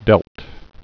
(dĕlt)